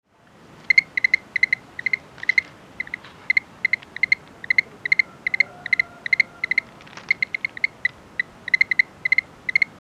Play Especie: Boophis luteus Género: Boophis Familia: Mantellidae Órden: Anura Clase: Amphibia Título: The calls of the frogs of Madagascar.
Localidad: Madagascar: Andasibe
Tipo de vocalización: Llamadas de suelta
22_4 Boophis Luteus.mp3